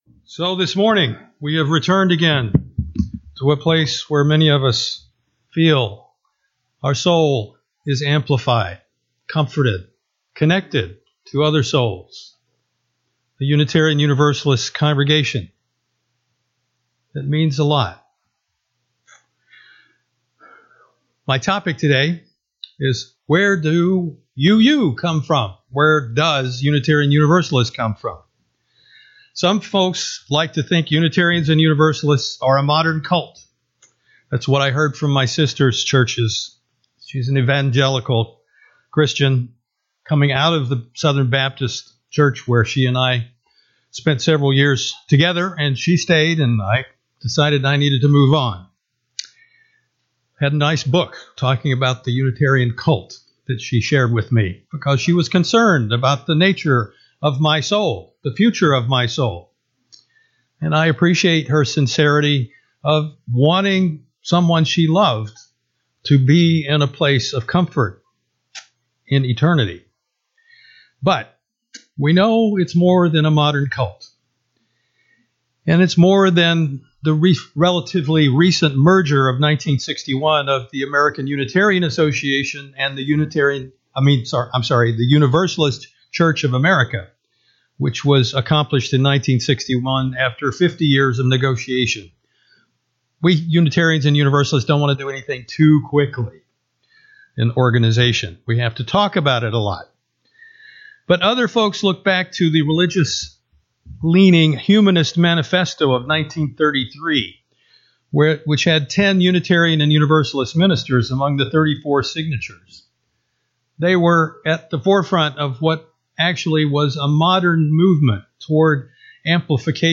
This sermon traces the evolution of Unitarian Universalism, refuting the idea that it is a modern cult by highlighting its ancient and complex roots.